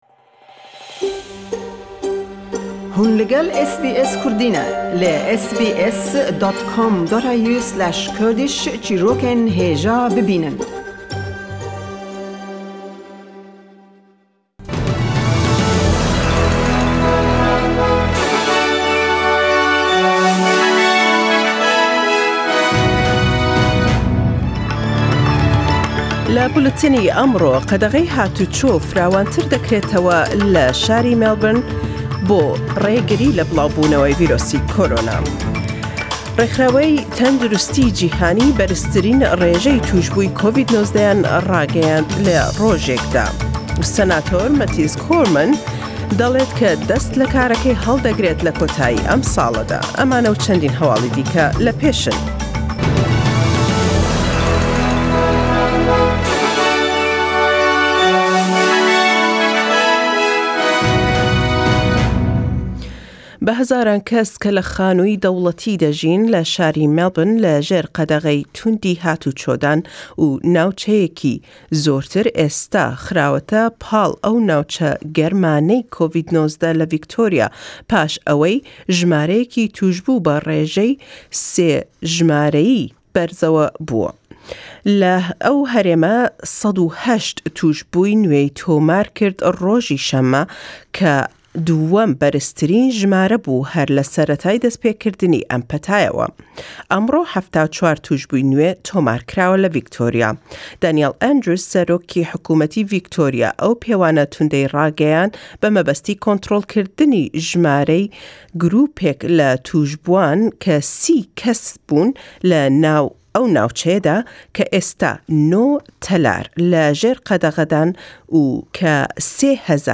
Buletinî hewallekanî rojî Yekşemme 05.07.2020 katjmêr 2pn be katî rojhellatî Australya.